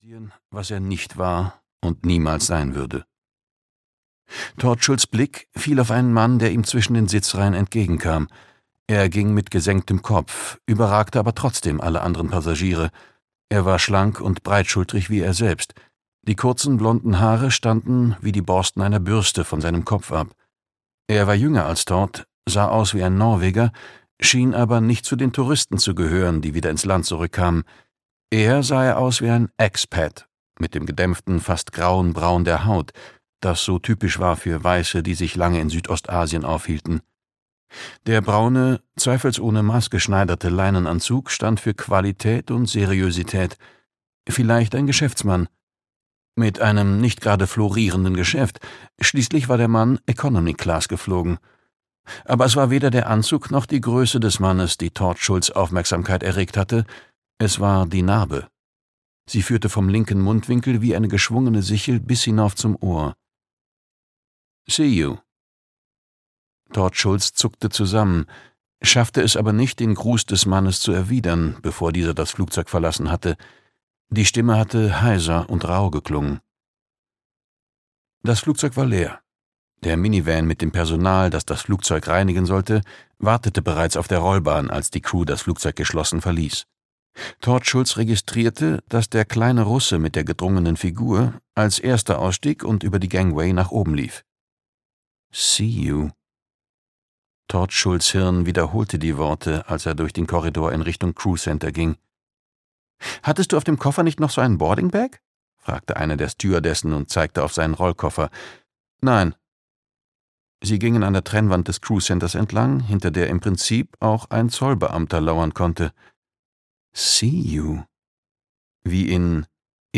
Hörbuch Larve (Ein Harry-Hole-Krimi 9), Jo Nesbø.